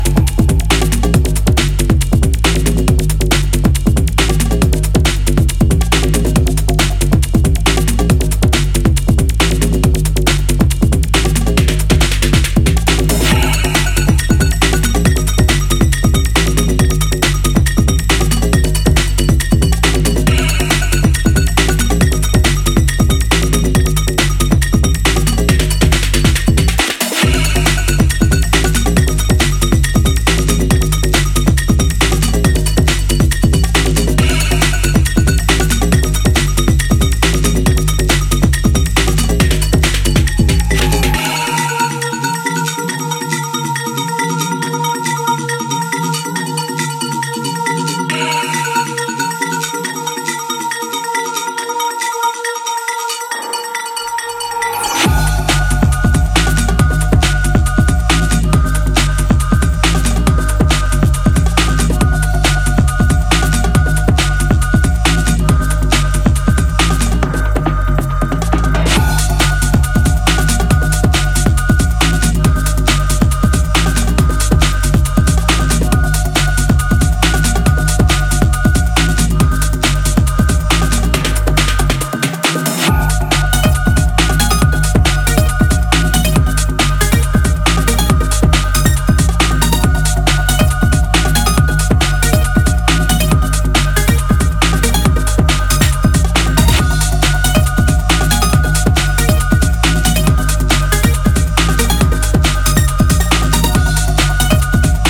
is a real bad boy that is sure to melt the dance floor